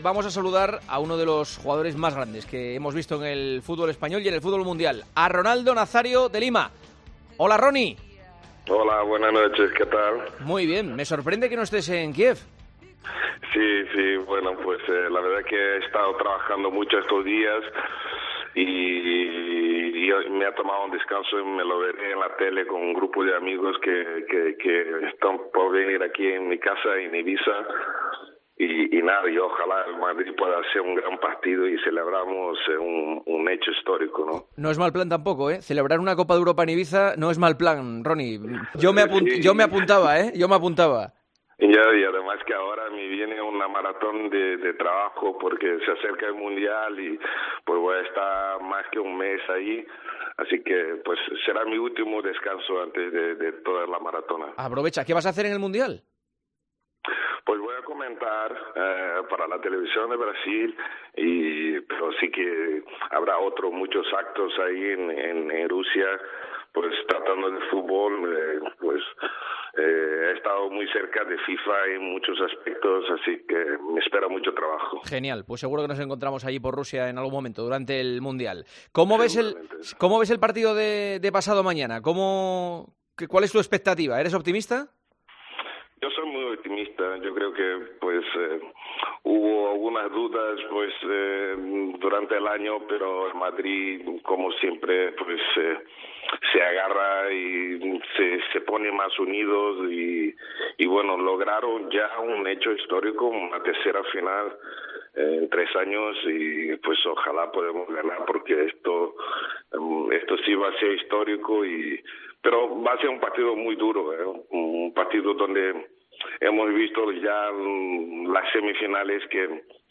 Juanma Castaño conversó con un mito del fútbol, Ronaldo Nazario de Lima , ex jugador del Barcelona y del Real Madrid , al que El Partidazo de COPE encontró descansando en Ibiza antes de pasar un largo mes en Rusia trabajando con motivo del Mundial de Rusia.